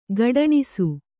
♪ gaḍaṇisu